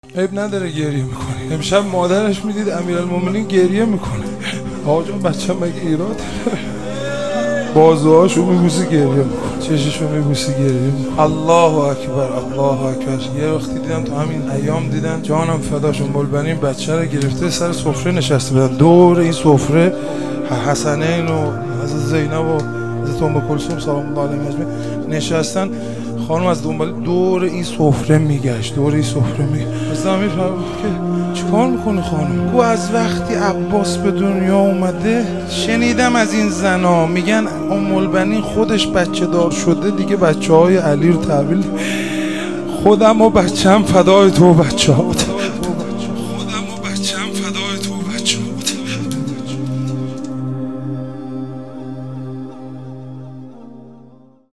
میلاد حضرت عباس (ع)